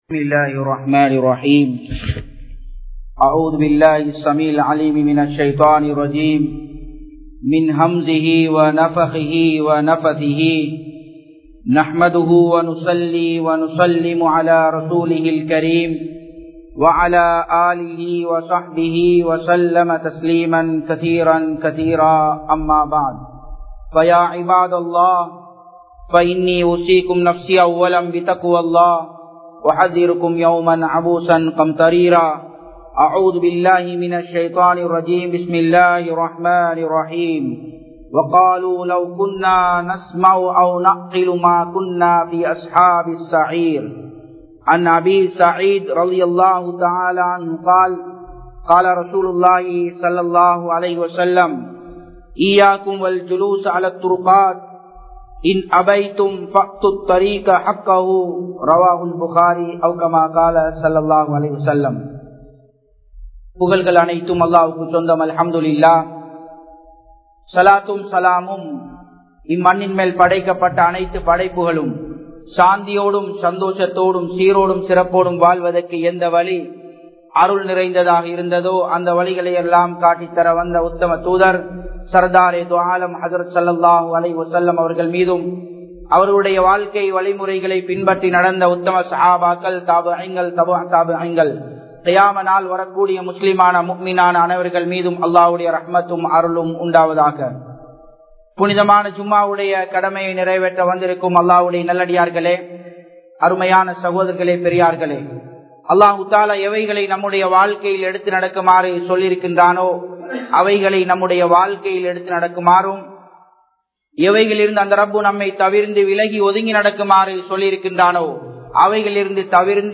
Mattravarhalin Urimaihalai Peanuvoam (மற்றவர்களின் உரிமைகளைப் பேணுவோம்) | Audio Bayans | All Ceylon Muslim Youth Community | Addalaichenai